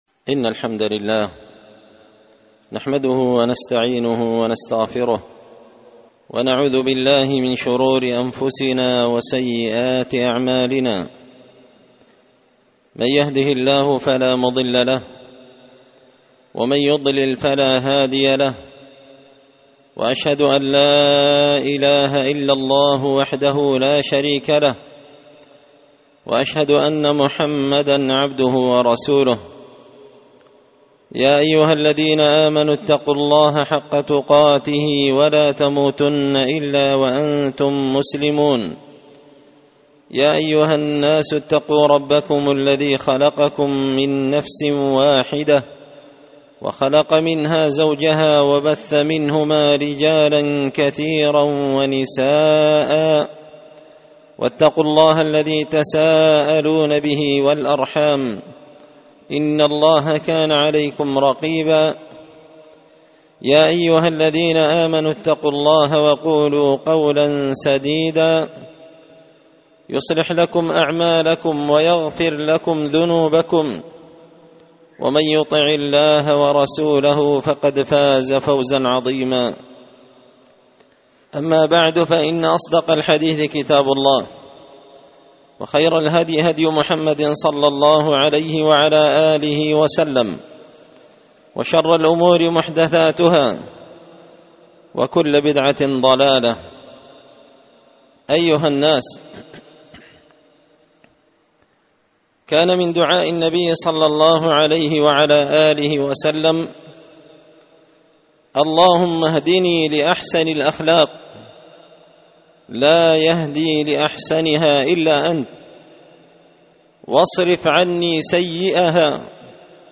خطبة جمعة بعنوان – الخيانة بئسة البطانة
دار الحديث بمسجد الفرقان ـ قشن ـ المهرة ـ اليمن
خطبة_جمعة_بعنوان_الخيانة_بئسة_البطانة_4جماد_ثاني_1443هـ_.mp3